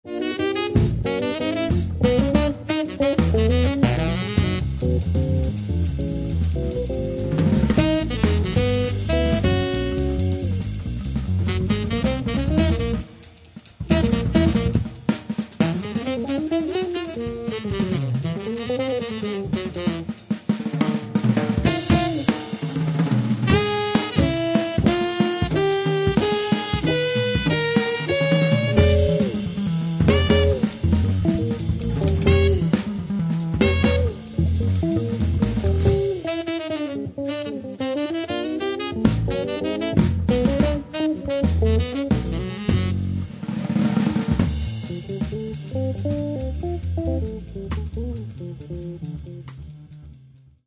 Каталог -> Джаз и около -> Сборники, Джемы & Live
guitar
tenor sax
acoustic bass
drums